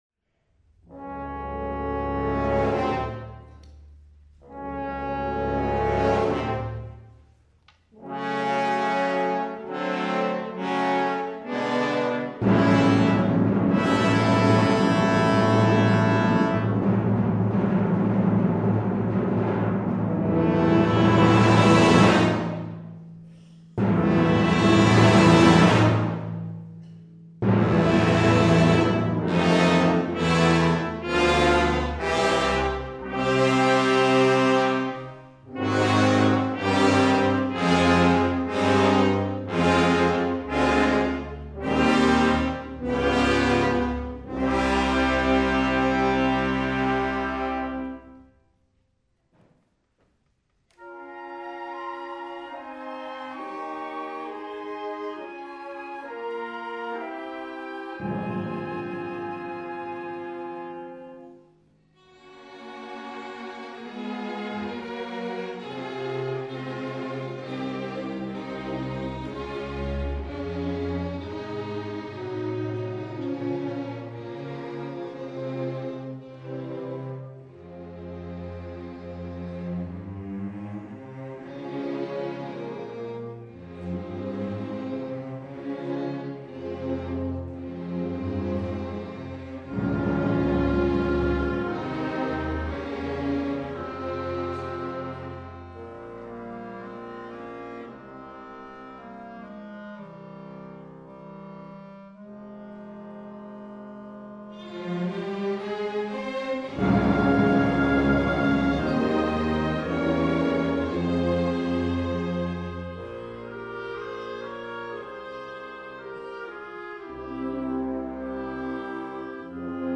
Sibelius later separated this final piece from its companions and presented it as a symphonic poem, giving it the now familiar title, Finlandia.